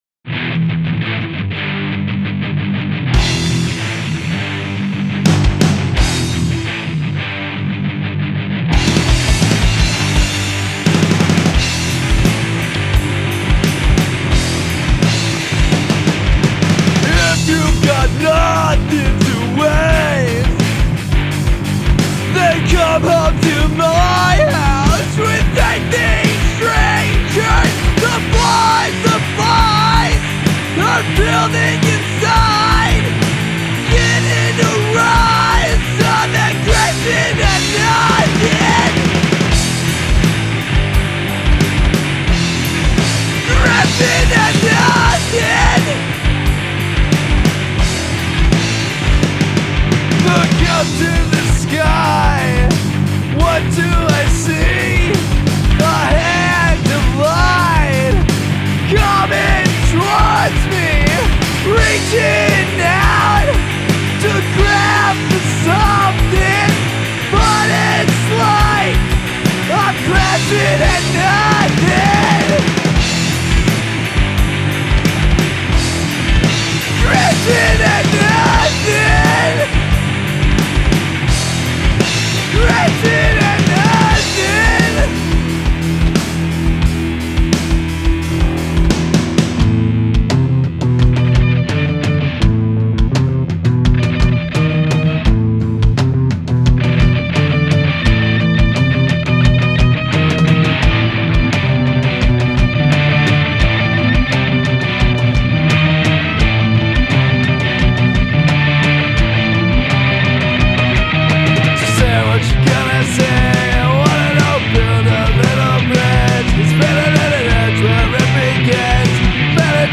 Songs we’ve recently recorded and/or mixed.